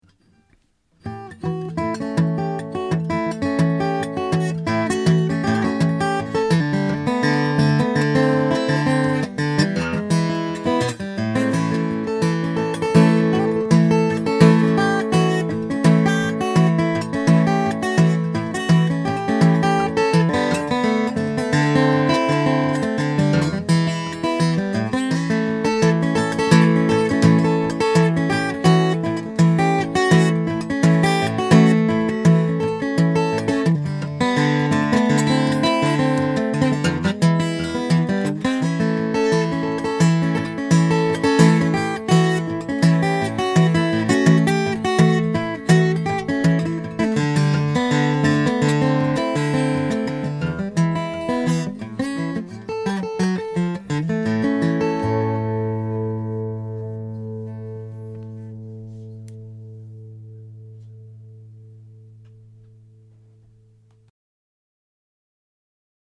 Tone is hard to describe, but with red spruce/ white oak, it's a very fundamental tone, really bluesy.